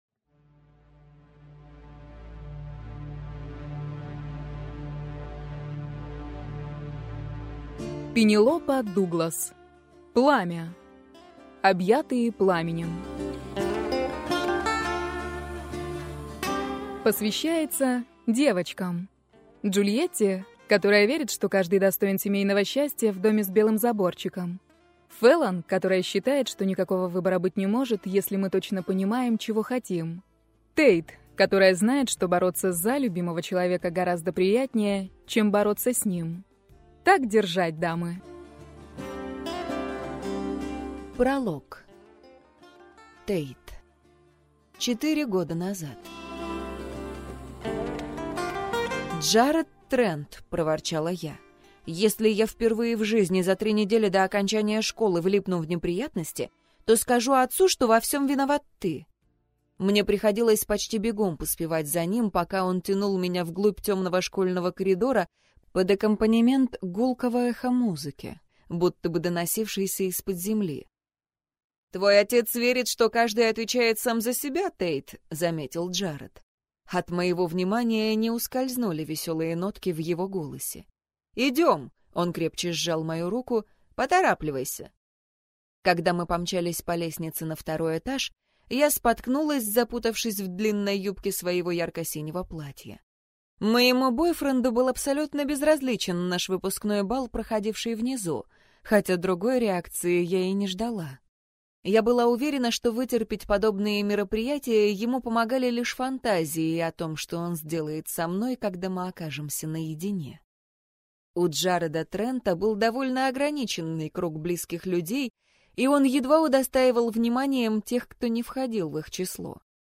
Аудиокнига Пламя - купить, скачать и слушать онлайн | КнигоПоиск